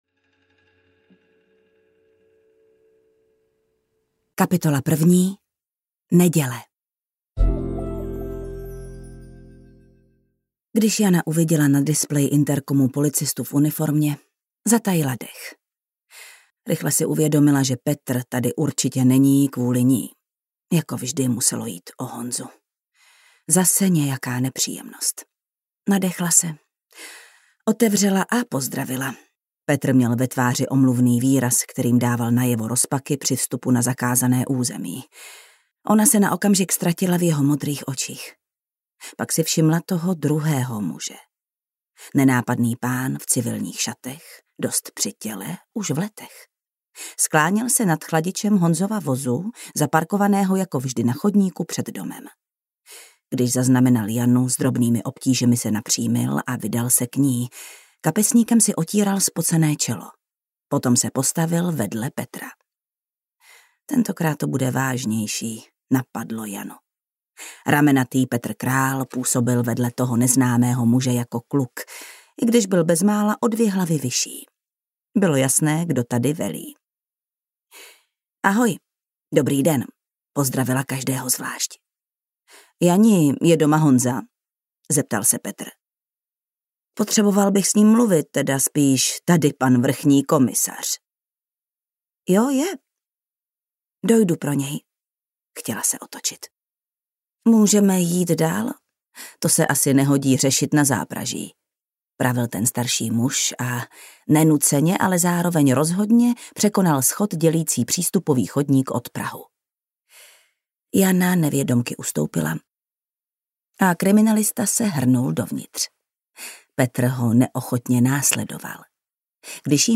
V kopřivách audiokniha
Ukázka z knihy
• InterpretJana Stryková
v-koprivach-audiokniha